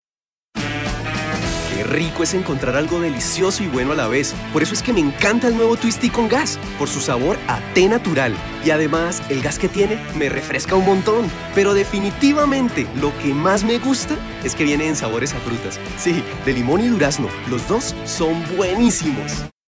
Friendly, warm, convincing, cheerful, and fresh for any kind of advertising project
spanisch Südamerika
kolumbianisch
Sprechprobe: Werbung (Muttersprache):